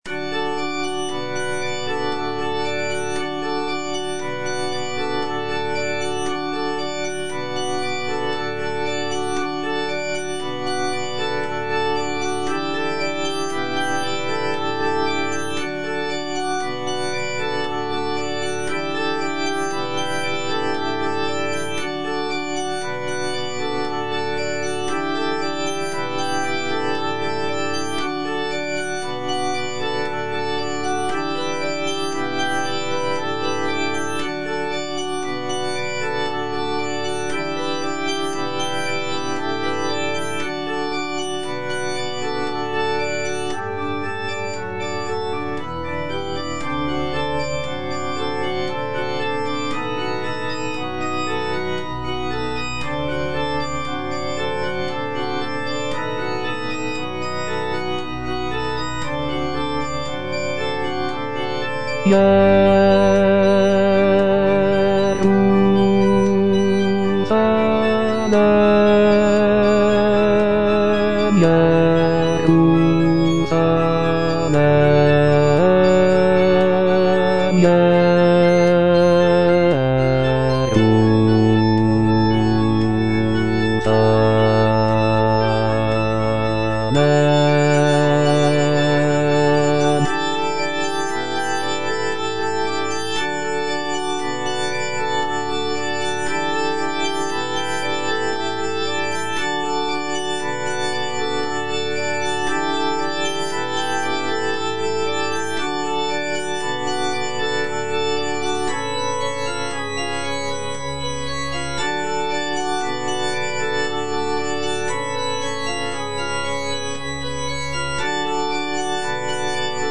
G. FAURÉ - REQUIEM OP.48 (VERSION WITH A SMALLER ORCHESTRA) In paradisum (bass I) (Voice with metronome) Ads stop: